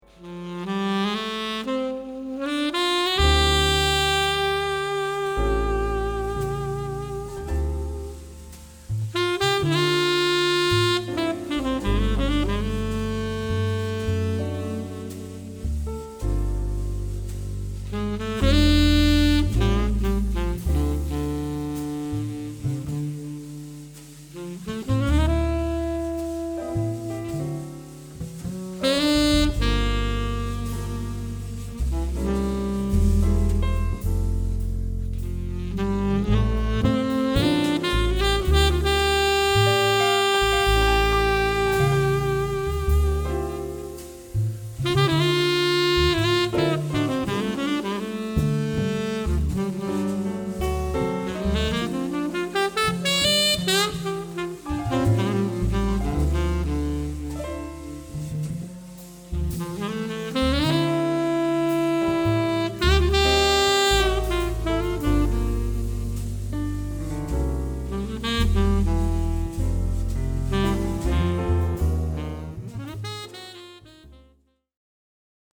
Soprano/Alto/Tenorsax/Altoflute
Piano/Organ/Rhodes
Double/Electric/Fretless Bass
Drums